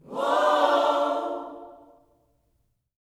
WHOA-OHS 8.wav